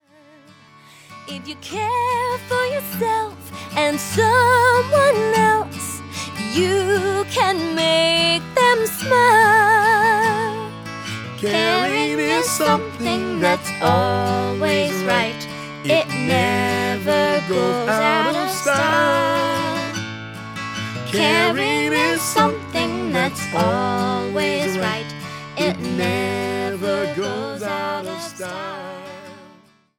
• MP3 of both vocals and instrumental